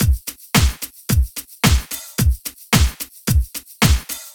03 Drumloop.wav